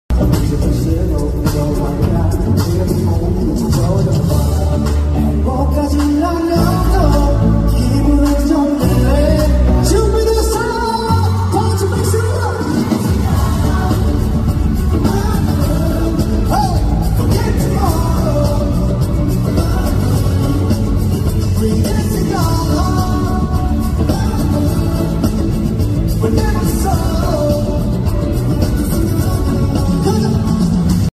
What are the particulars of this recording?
Asia Tour in Kuala Lumpur